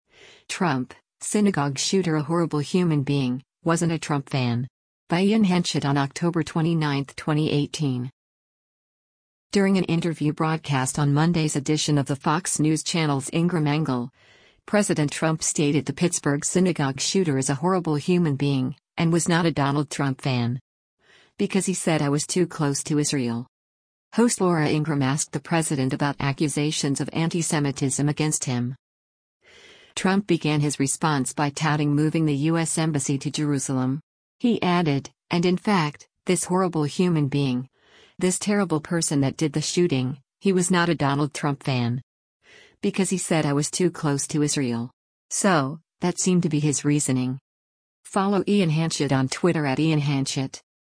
During an interview broadcast on Monday’s edition of the Fox News Channel’s “Ingraham Angle,” President Trump stated the Pittsburgh synagogue shooter is a “horrible human being,” and “was not a Donald Trump fan. Because he said I was too close to Israel.”
Host Laura Ingraham asked the president about accusations of anti-Semitism against him.